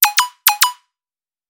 Catégorie: Messages - SMS